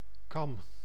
Ääntäminen
US : IPA : [ˈbrɪdʒ]